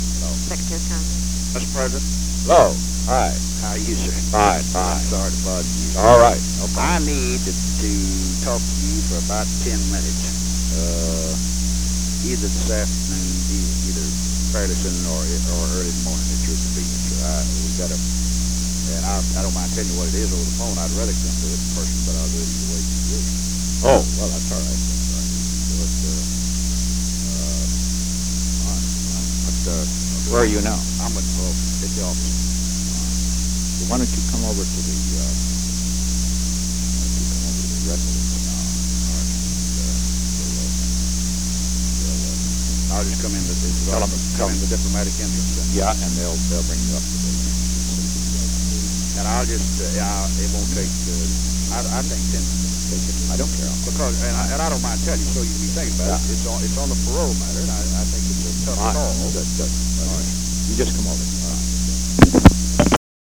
Secret White House Tapes
Conversation No. 3-4
Location: White House Telephone
John B. Connally talked with the President.